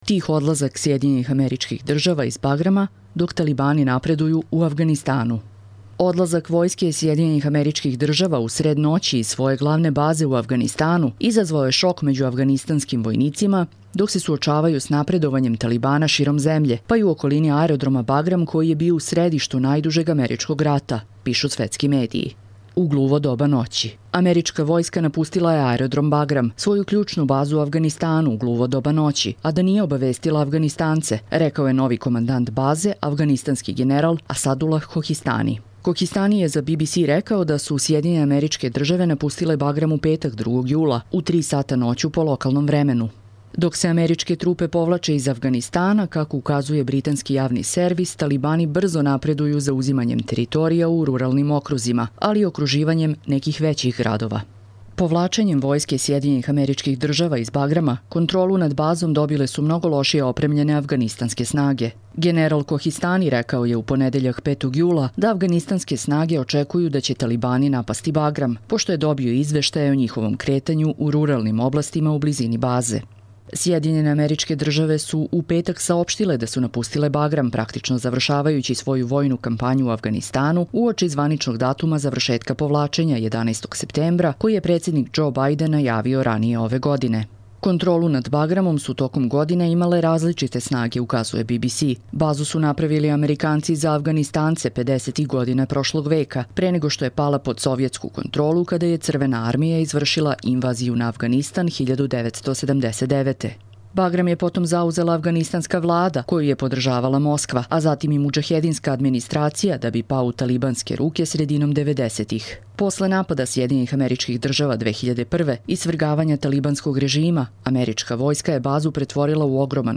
Čitamo vam: Tih odlazak SAD iz Bagrama dok talibani napreduju u Avganistanu